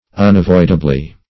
unavoidably - definition of unavoidably - synonyms, pronunciation, spelling from Free Dictionary